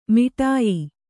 ♪ miṭāyi